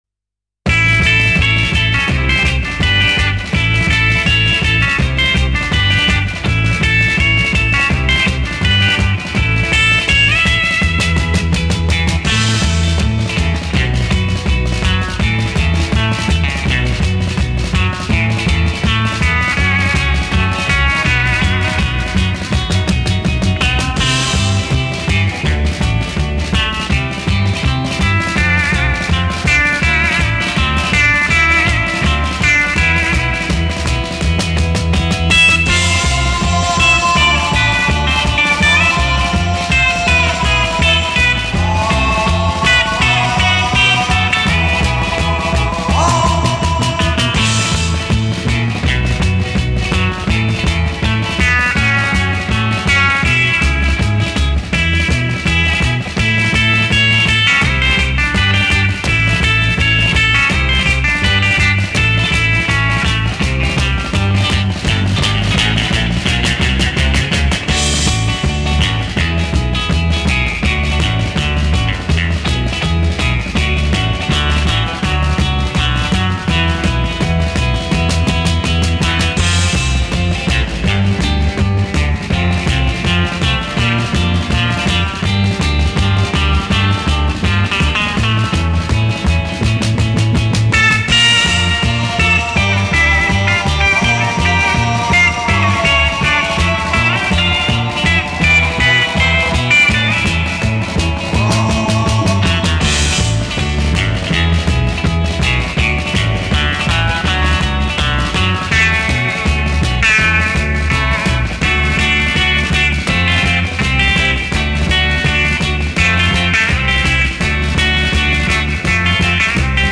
инструментальная группа 60-х годов XX века.